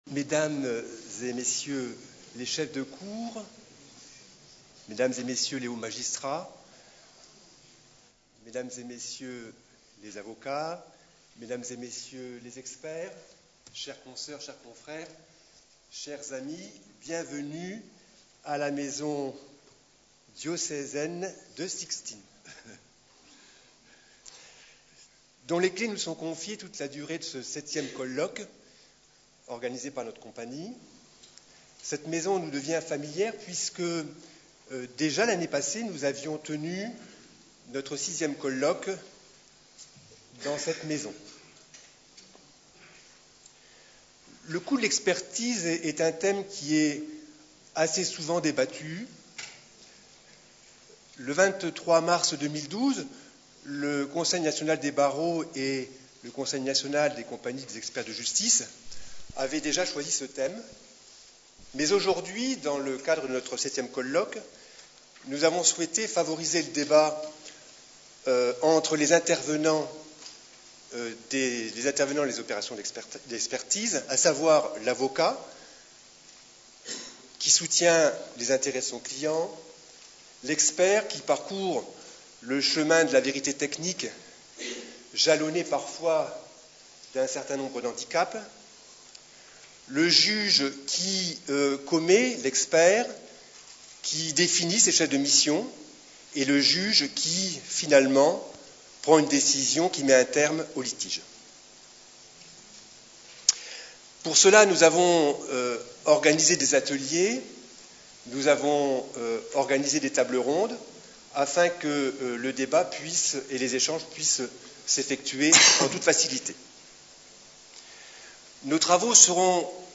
Conférence enregistrée lors du 7ème Colloque de la Compagnie des Experts de Reims.